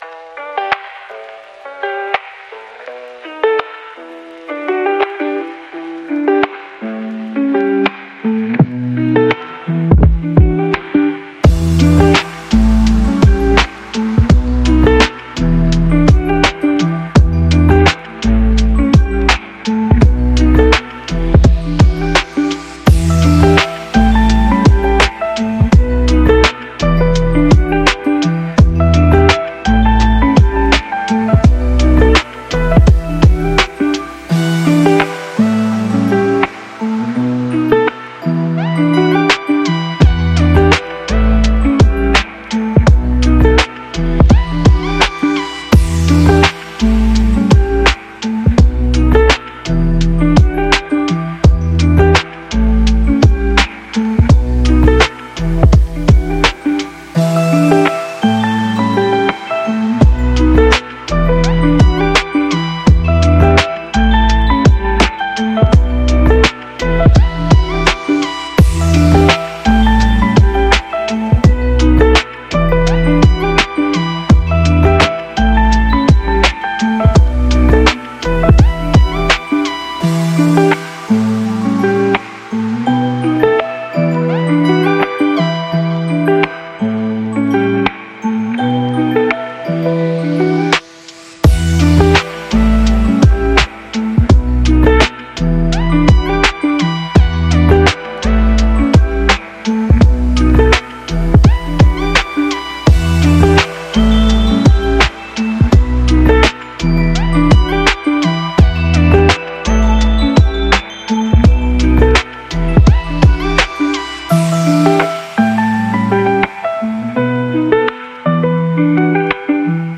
Lofi Podcast